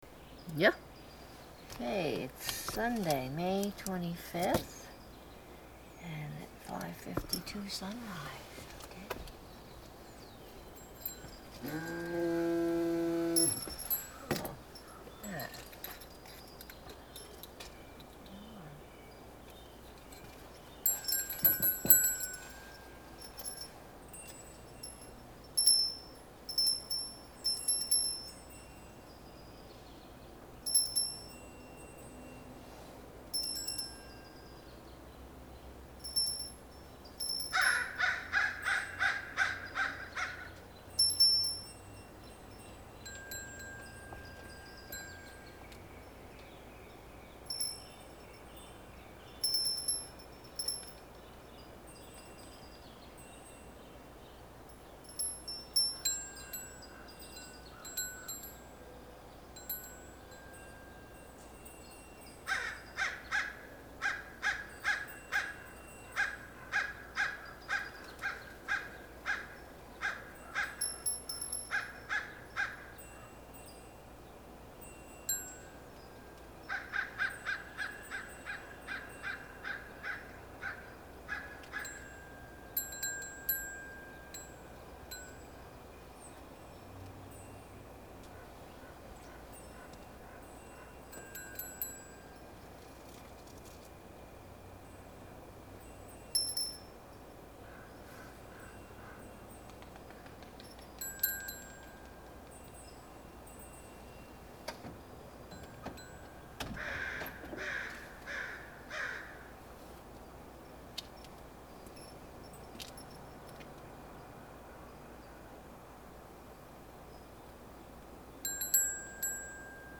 This one for bells and crow- inside the house and out in the yard.
The crow is as vocal as ever.